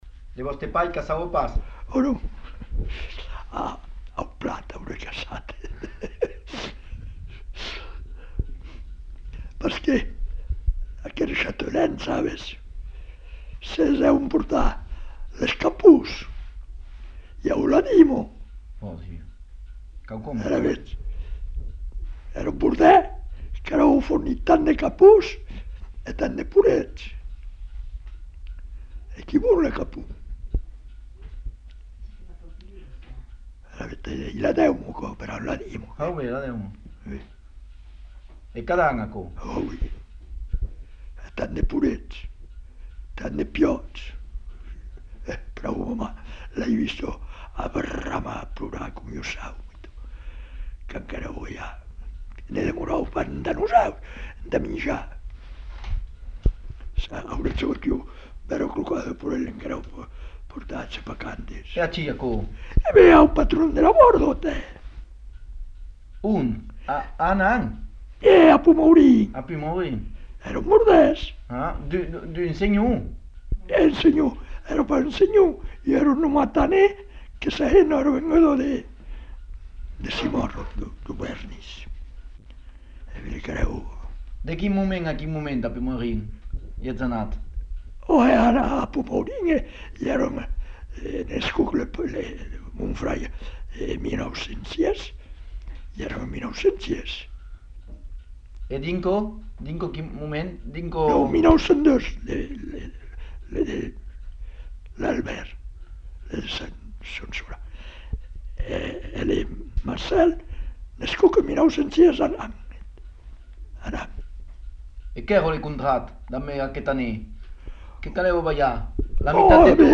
Lieu : Monblanc
Genre : récit de vie